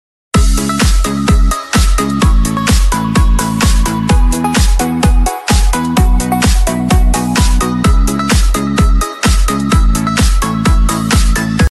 300ml 330ml Pet Can Soft Sound Effects Free Download